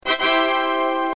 Tada Sound Buttons
tada
tada1.mp3